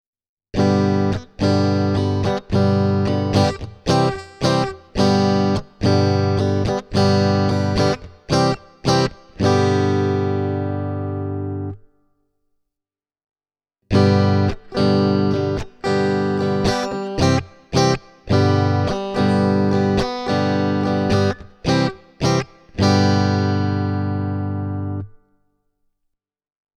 Äänitin koesarjan eri kitaroilla Blackstar HT-1R -putkikombolla niin, että jokaisessa klipissä ensimmäinen puolisko on äänitetty pelkästään Whirlwind-johdolla ja sitten toisessa on lisätty Spin X -johto signaalitiehen.
Ensin Fender Stratocaster ilman vahvistinta:
Spin X:n kanssa soundissa on vähemmän raapivaa terävyyttä diskantissa, enemmän avoimuutta ja kiiltoa ylä-middlen preesensalueella, sekä tiukempi ja tarkempi bassotoisto.
spin-x-cable-e28093-strat-di.mp3